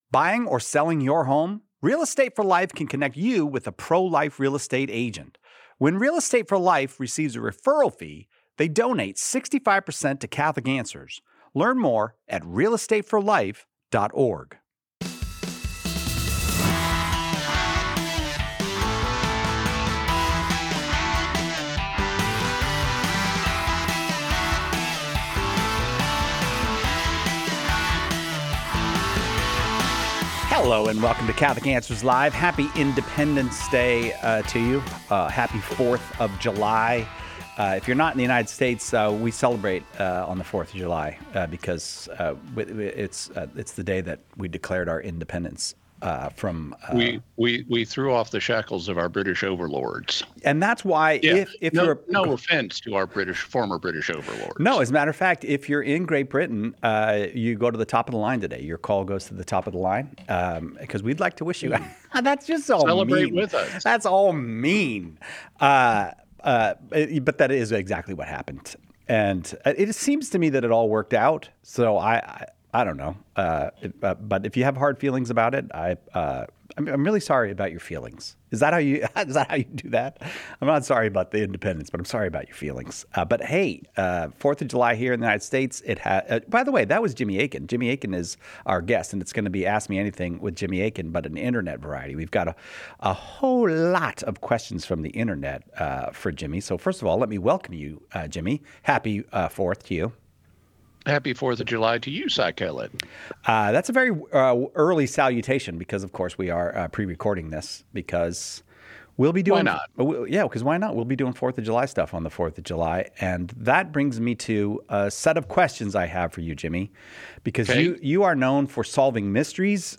On this unique Fourth of July edition of Catholic Answers Live , we dive into five fun mysteries of Independence Day. Plus, callers ask deep theological questions: Did Christ always have a human nature?